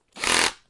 冲击、撞击、摩擦 工具 " 短柄钻头
标签： 工具 工具 崩溃 砰的一声 塑料 摩擦 金属 冲击
声道立体声